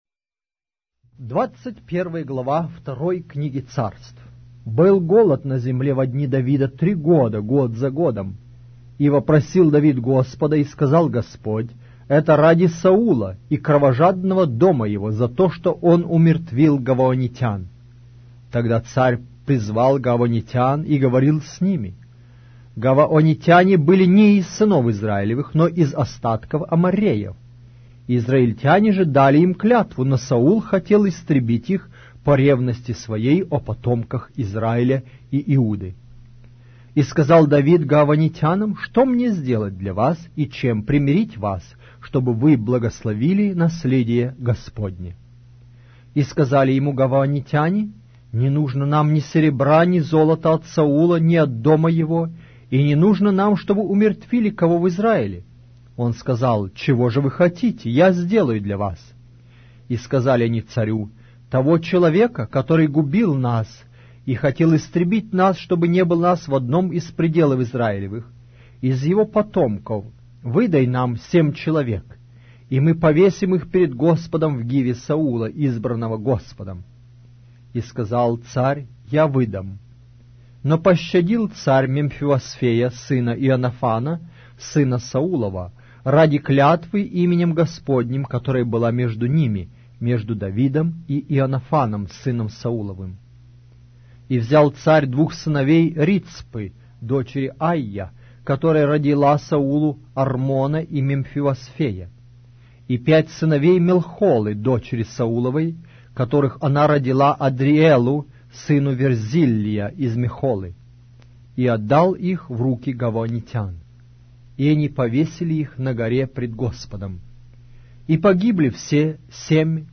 Аудиокнига: 2-я Книга Царств